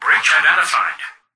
"Breach identified" excerpt of the reversed speech found in the Halo 3 Terminals.
H3_tvox_fix2_breachidentified_(unreversed).mp3